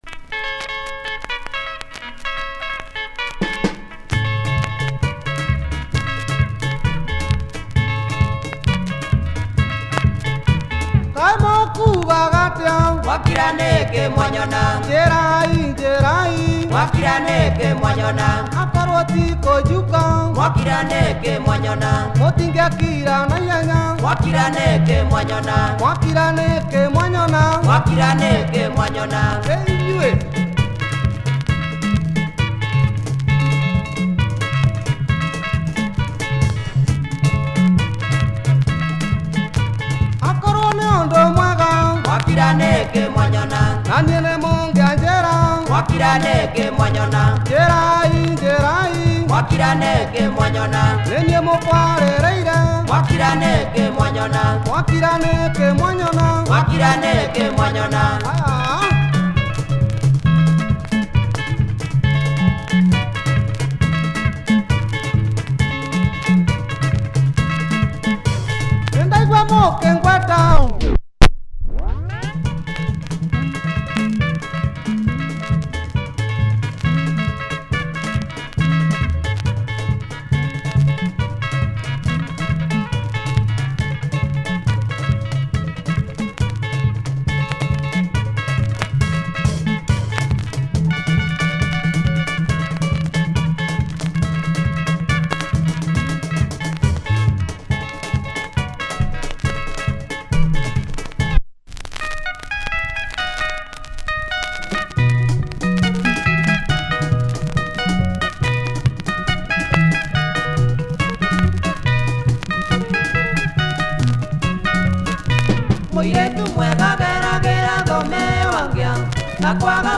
Benga from this prolific Kikuyu group. https